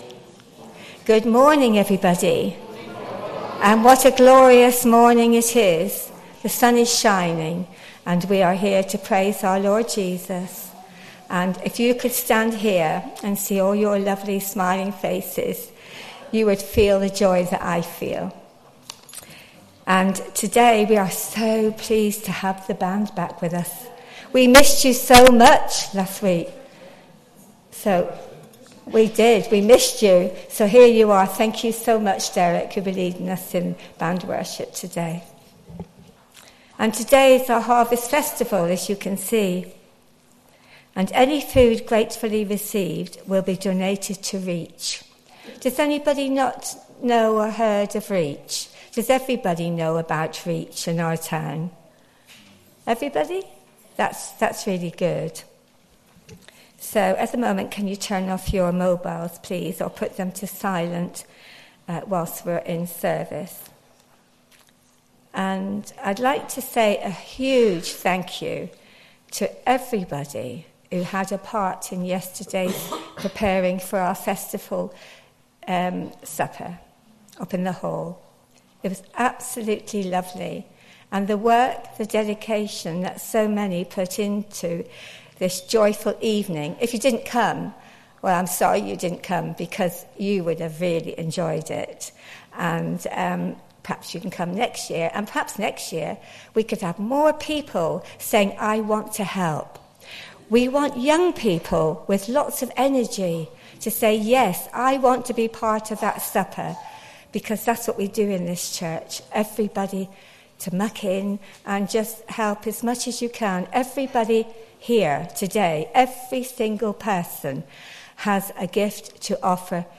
Service Type: All Age Worship
10-06-whole-service.mp3